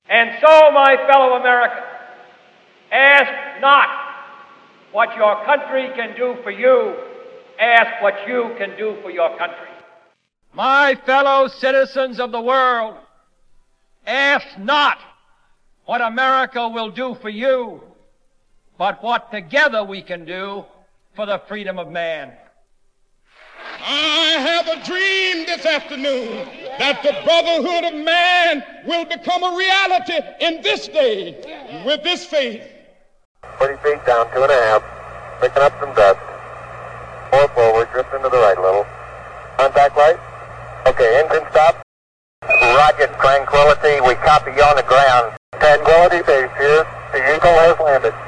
The middle of the piece contains audio execrates from John F. Kennedy’s inauguration speech, Martin Luther King’s “I Have a Dream” speech and the 1969 Apollo 11 Moon landing.
Narration Track  (Available from Sheet Music Plus and Sheet Music Direct).
golden-dream-narration.mp3